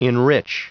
Prononciation du mot enrich en anglais (fichier audio)
Prononciation du mot : enrich